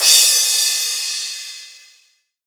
Crashes & Cymbals
Lod_Crsh.wav